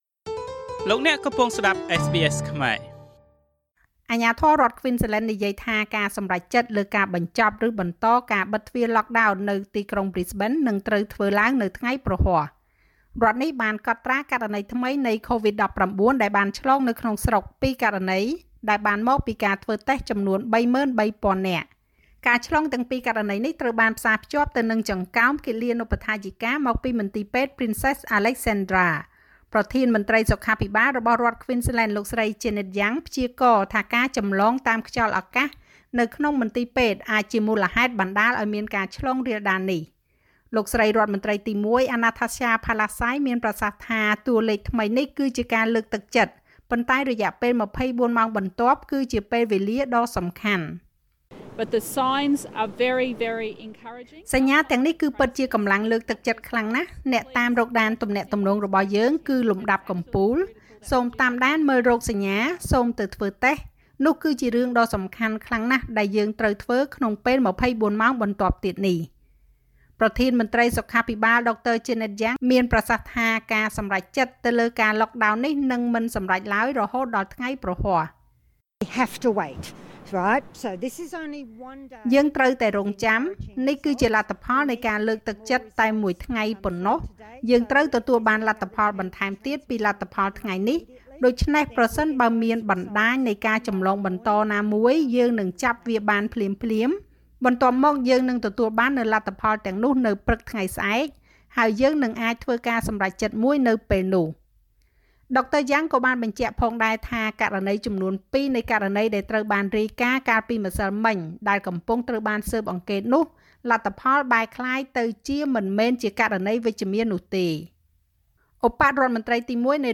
នាទីព័ត៌មានរបស់SBSខ្មែរ សម្រាប់ថ្ងៃពុធ ទី៣១ ខែមីនា ឆ្នាំ២០២១។ ** អាជ្ញាធរគ្វីនស្លេននិយាយថា ការសម្រេចចិត្តលើការបញ្ចប់ ឬបន្តការបិទទ្វារlockdown នៅប្រ៊ីសបេននឹងសម្រេចនៅថ្ងៃព្រហស្បតិ៍។ **សង្រ្គាមពាក្យសំដីនៃការចាក់វ៉ាក់សាំងបង្ការកូវីដ បានផ្ទុះឡើងរវាងរដ្ឋាភិបាលសហព័ន្ធនិងរដ្ឋាភិបាលថ្នាក់រដ្ឋ។